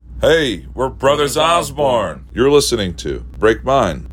LINER Brothers Osborne (Break Mine) 4